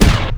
SmallExplosion.wav